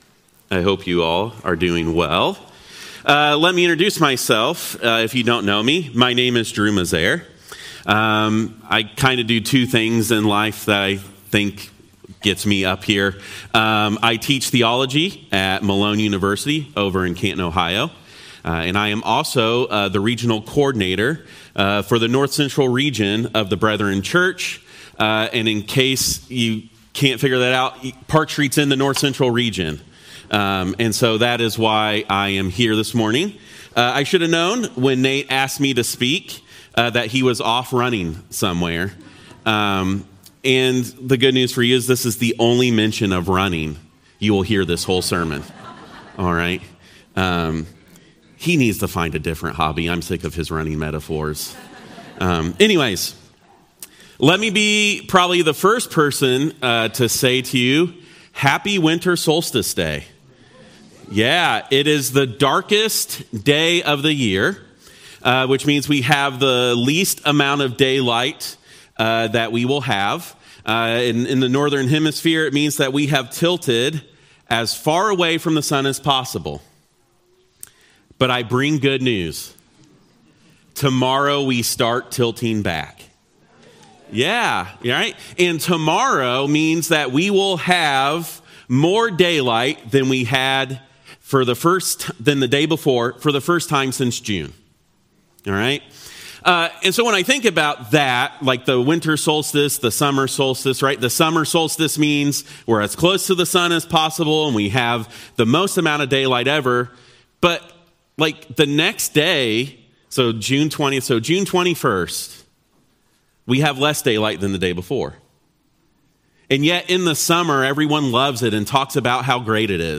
Sermons - Park Street Brethren Church
Sermon Series